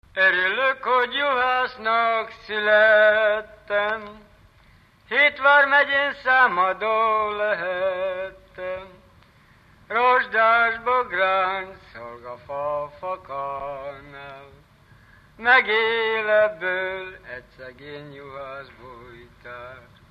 Alföld - Pest-Pilis-Solt-Kiskun vm. - Kecel
ének
Stílus: 4. Sirató stílusú dallamok
Kadencia: 6 (5) 2 1